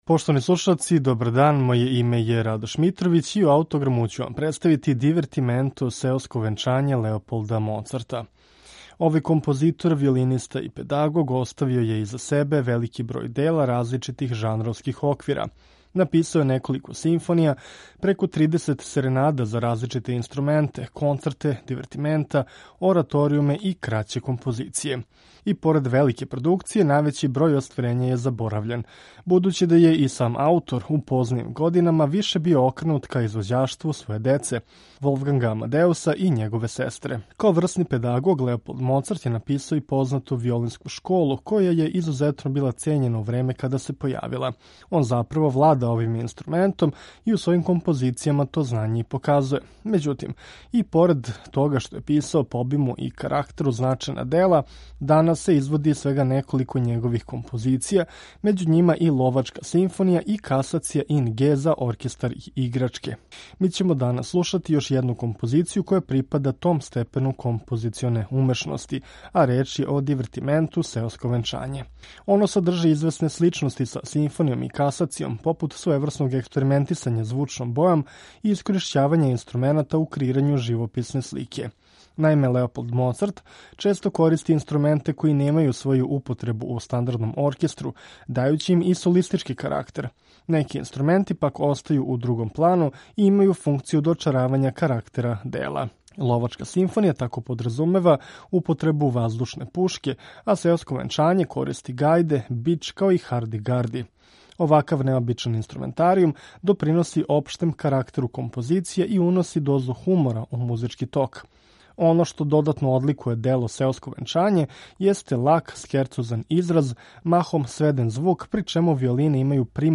Уз мноштво необичних инструменталних боја, ово дело поседује карактер пун духа и хумора. Слушамо га у интерпретацији Амстердамског барокног ансамбла, под управом Тона Купмана.